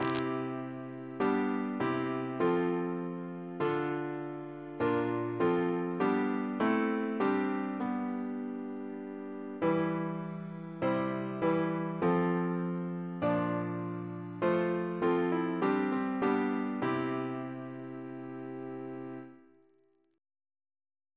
Double chant in C Composer: David Hurd (b.1950) Reference psalters: ACP: 230